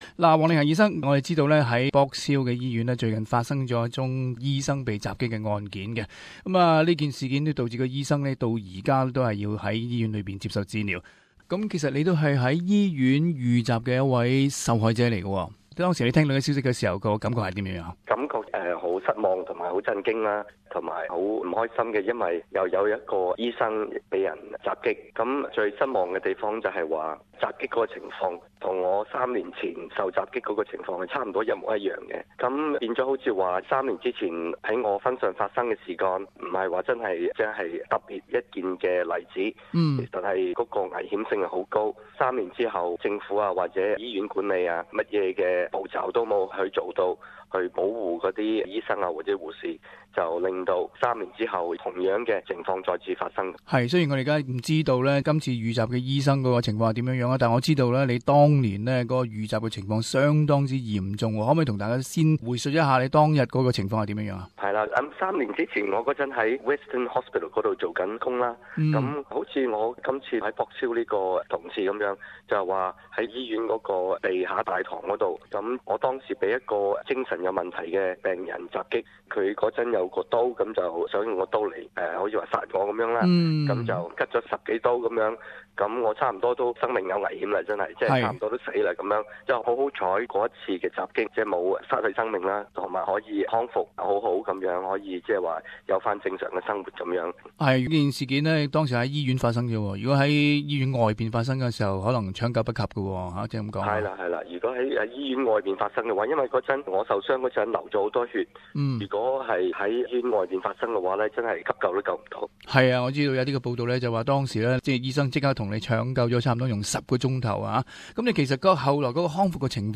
【社團專訪】醫護人員遇襲事件屬誰之過？